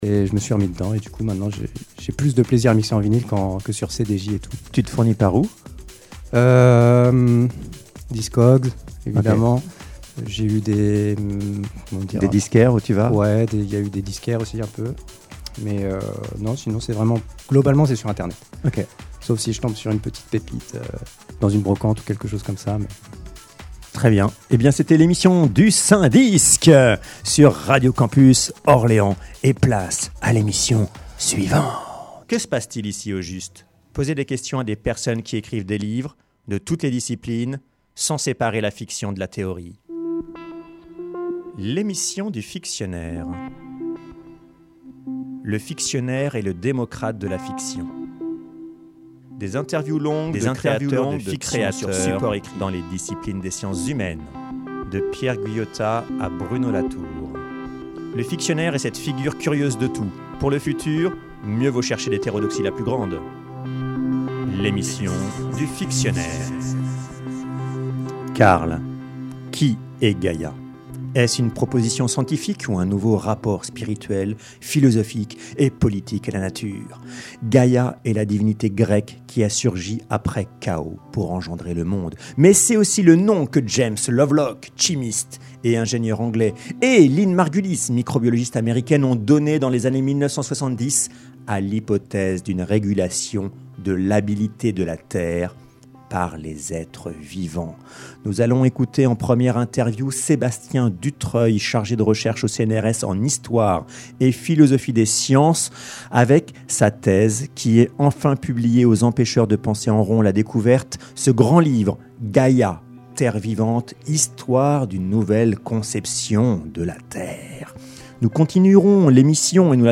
Au cours de cette émission sont interviewés des écrivains, poètes, romanciers, essayistes, traducteurs, chercheurs, sociologues, anthropologues, philosophes et éditeurs français.